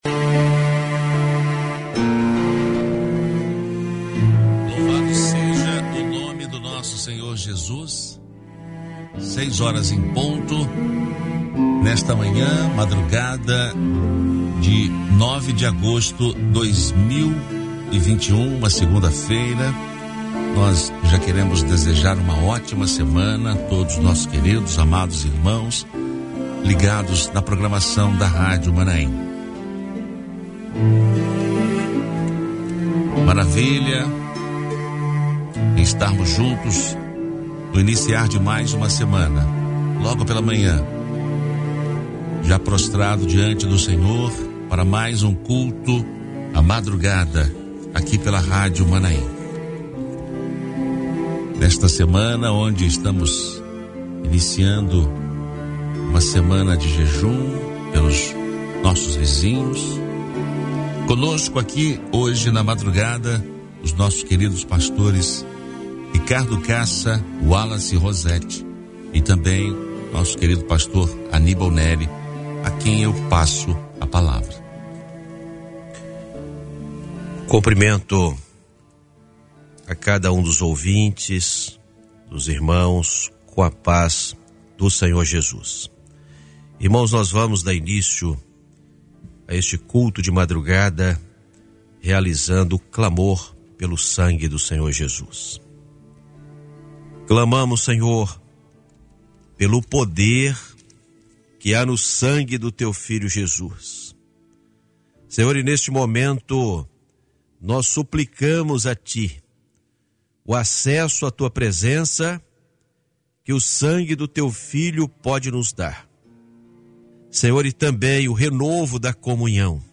Culto de oração transmitido dentro do programa Bom Dia Maanaim